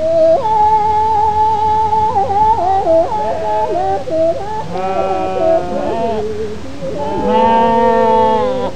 Nomad singing
nomadsings.aif